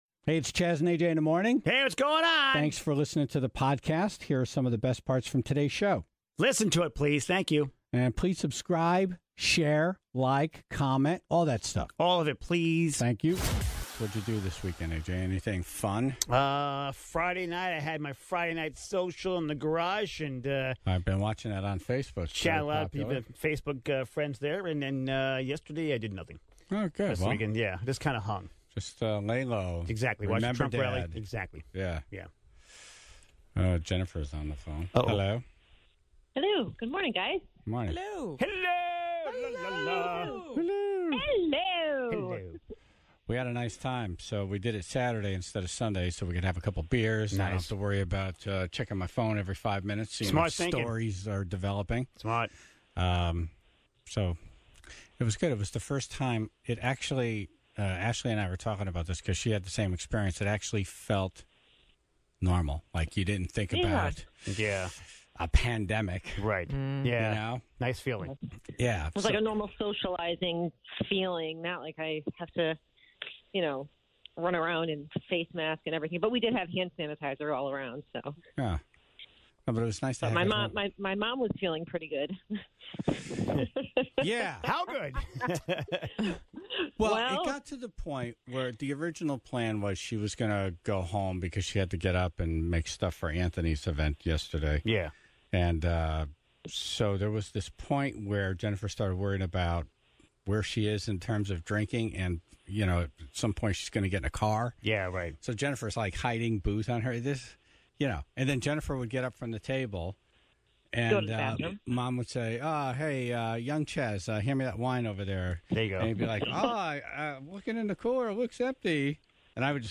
(10:12) New Britain Mayor Erin Stewart on to talk about the town's plans to reopen pools this coming weekend, and her options for baby names.